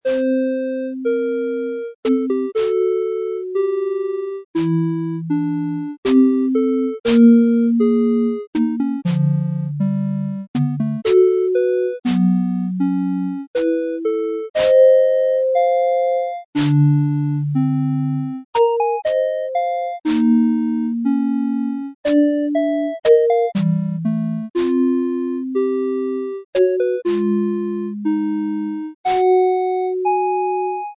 This class extenmds the scoe to use three instruments and uses two contrapuntal lines.
The firat two parts play the same melody in unision and the third part contains a counter melody.
The pitch of the first of the counter melody note pairs is set to either a minor 3rd, fourth, fifth, major 6th or octave above the melody note.
The result is an active and fragmented descant above the original part, creating a predominatly tonal but occasionaly dissonant harmonic texture.